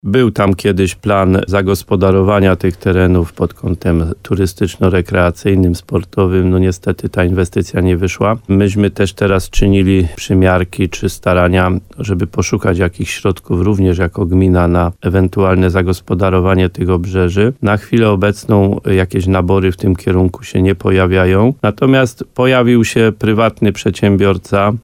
W porannej rozmowie Słowo za Słowo na antenie RDN Nowy Sącz podkreślał, że samorząd sam nie był w stanie wykorzystać tego miejsca.